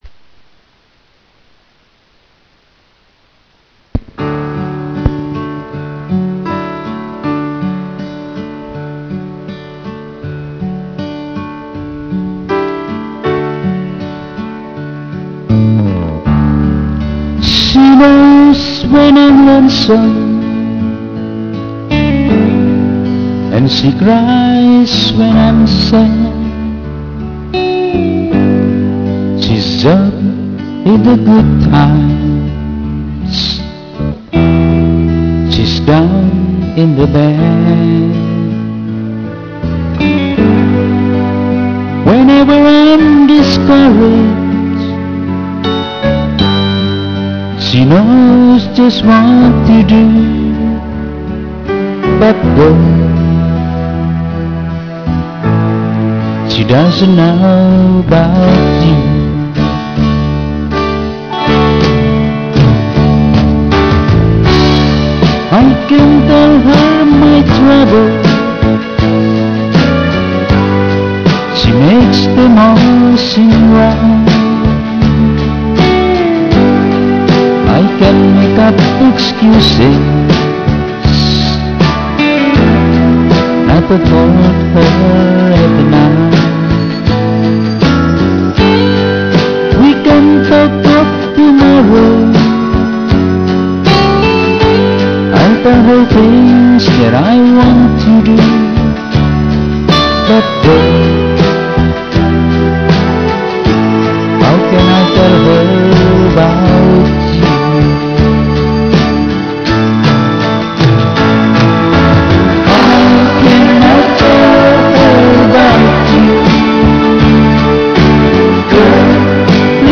Saya merekam suara saya sendiri.
Suara pas-pasan aja pamer.